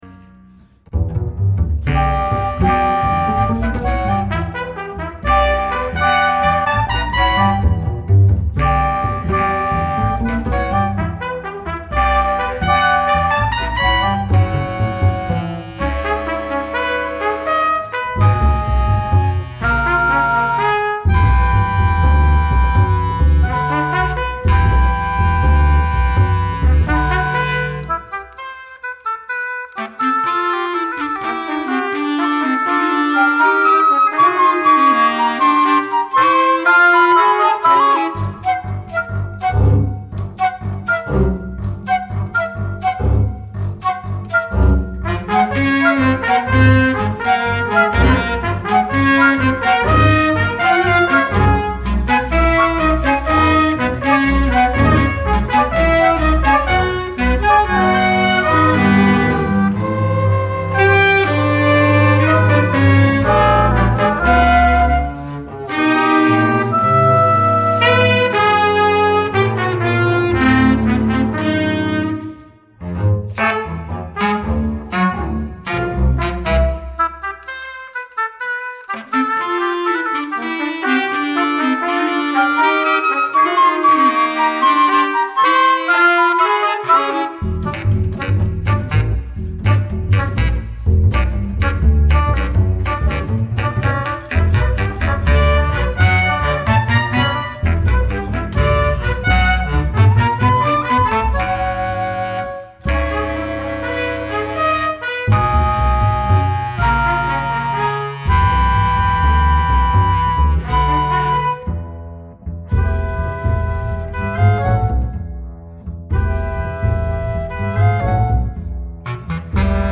musiche invisibili per 6 esecutori e 11 strumenti